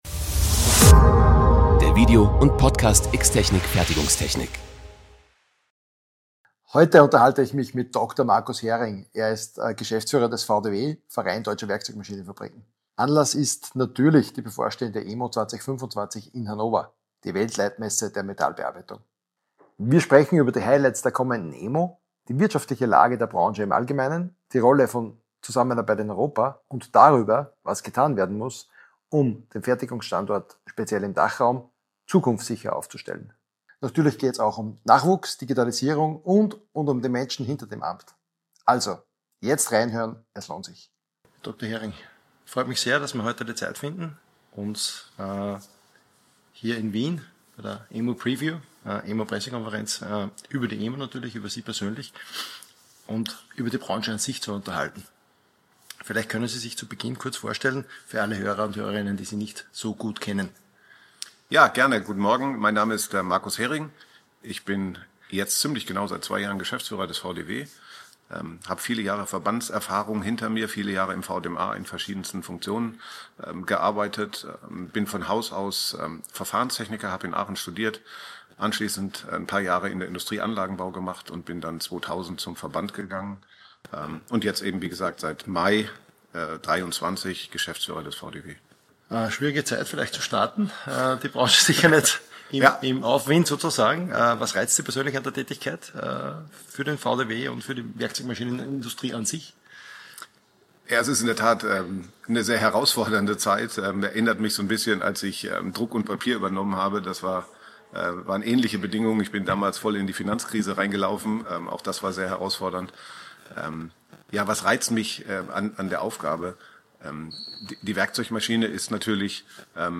Podcast-Gespräch